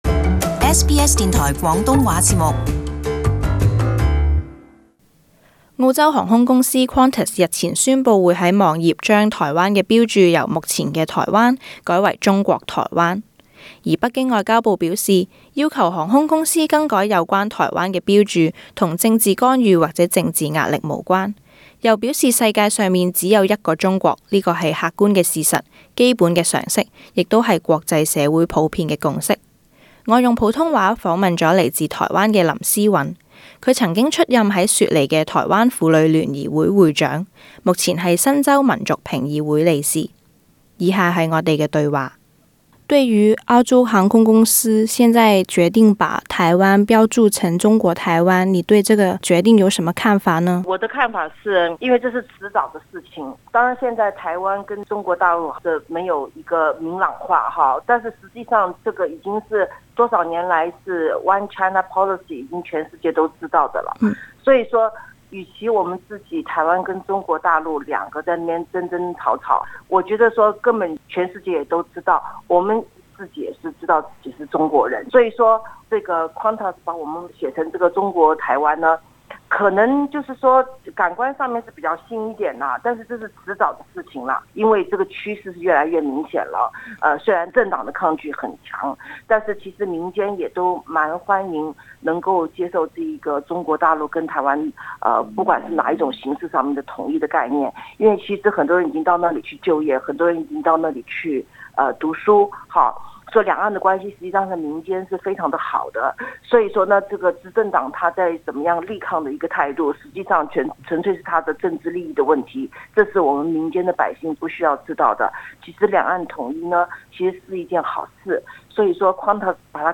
專訪: 中台兩岸統一只是遲早的事？